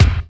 SWING BD 4.wav